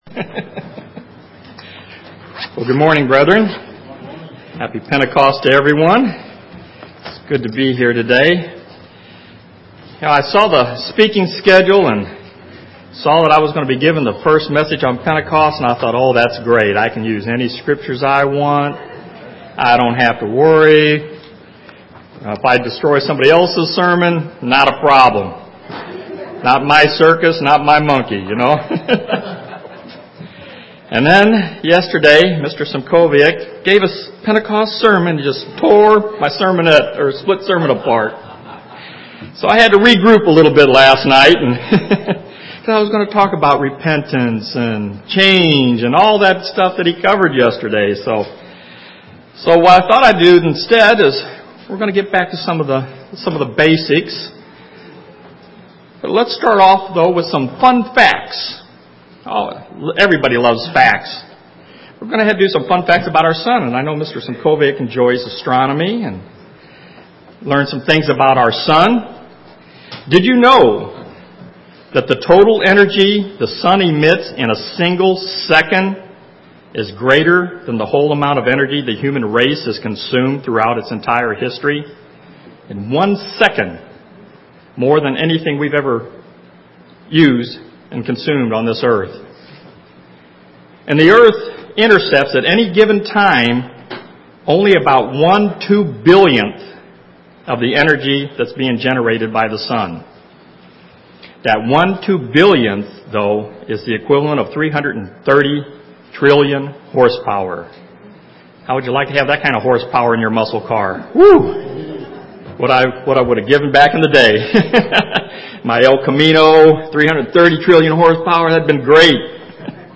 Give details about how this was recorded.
This split sermon was given on the Feast of Pentecost.